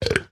burp.ogg